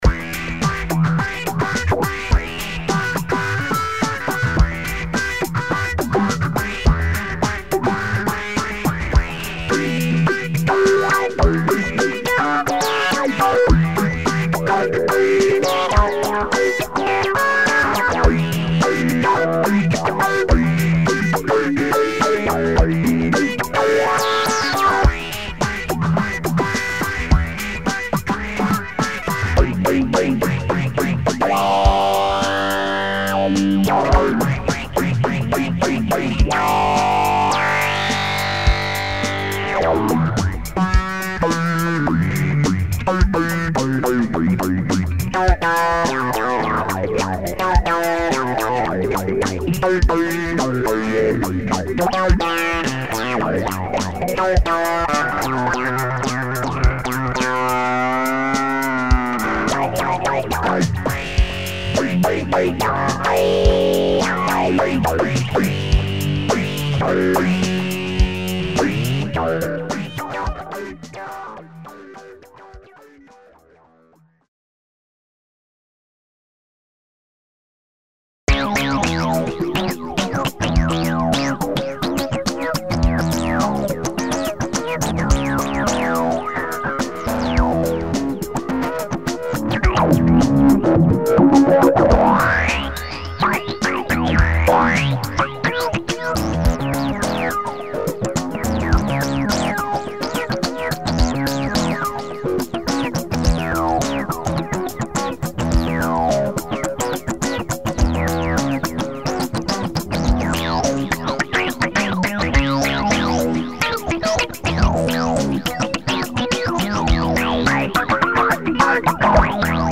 3. Two electric guitar examples, directly into the main input. Auto functions (Audio-Trigger + envs) AND a pedal for cutoff controlling are used. After the main output comes a little bit reverb.
Guitar_2Tracks.mp3